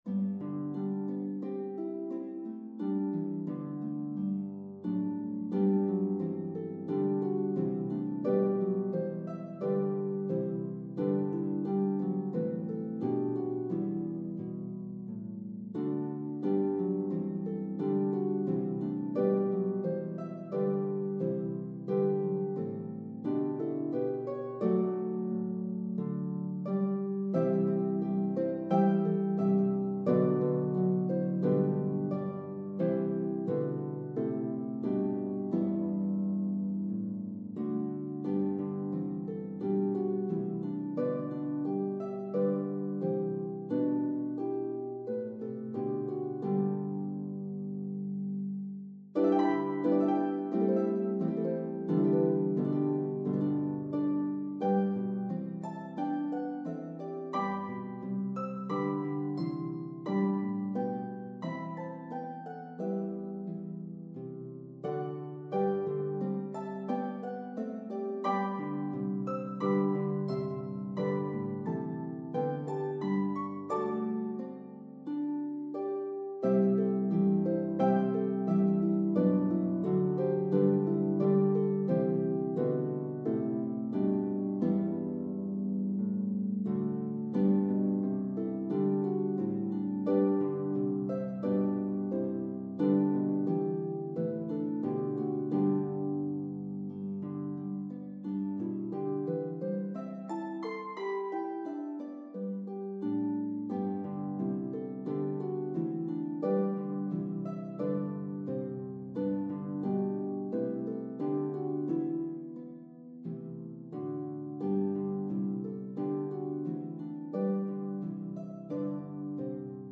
There is a chromatic in each verse.